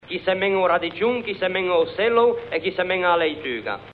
Si “ottiene” il dittongo “o-u” /'Ow/, che si trova riportato nella tabella dedicata ai “dittonghi discendenti” contenuta nell'articolo “I dittonghi e gli iati della lingua genovese” presente sul sito come “appendice” alle “Osservazioni sulla grafia di G. Casaccia”.